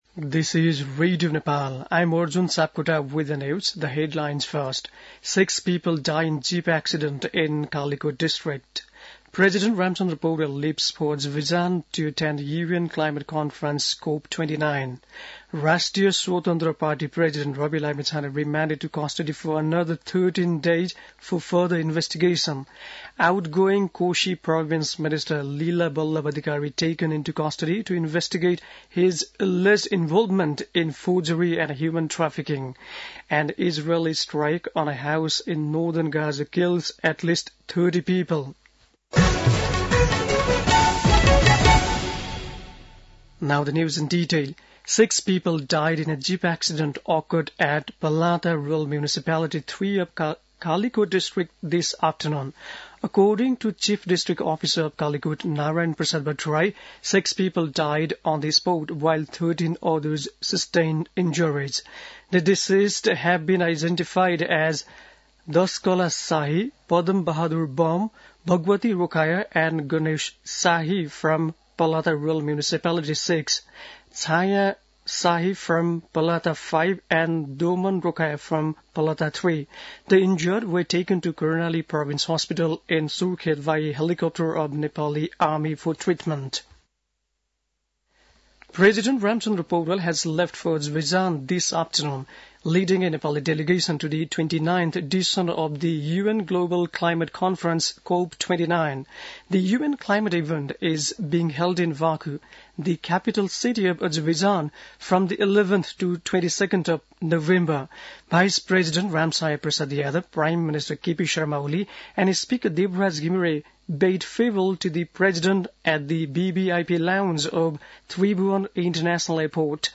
बेलुकी ८ बजेको अङ्ग्रेजी समाचार : २६ कार्तिक , २०८१
8-pm-english-news.mp3